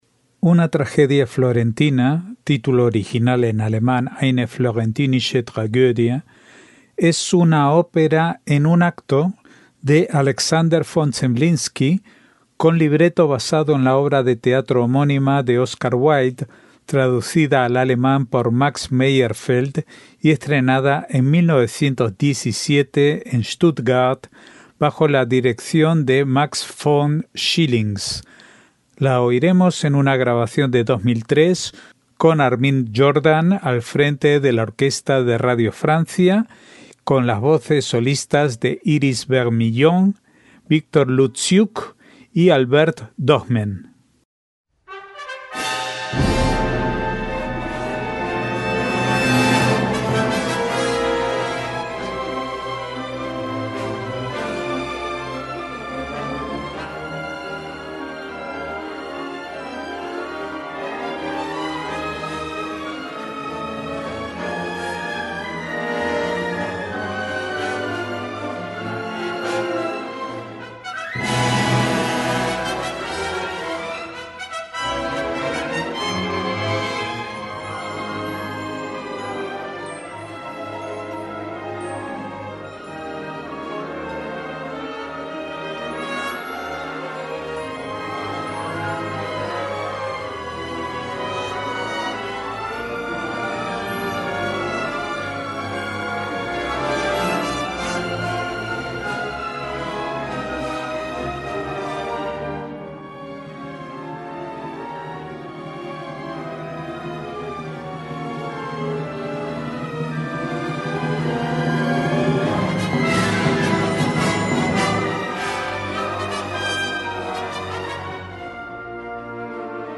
voces solistas